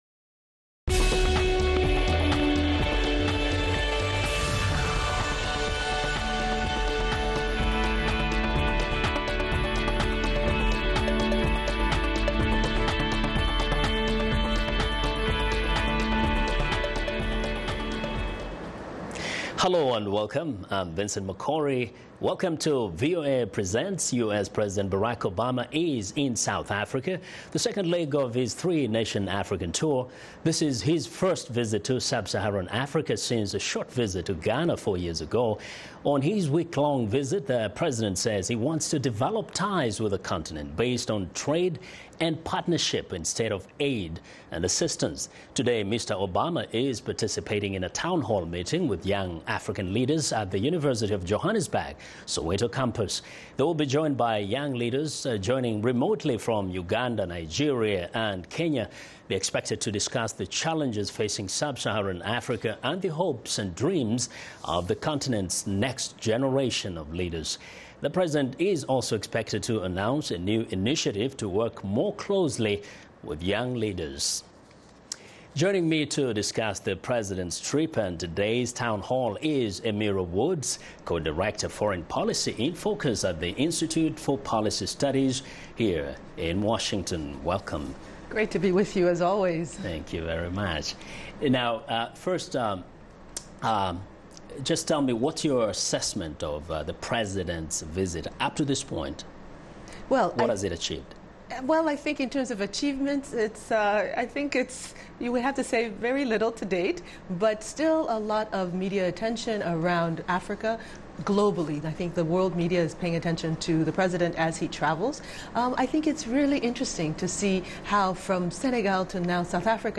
Listen to the entire town hall meeting at the University of Johanesburg - Soweto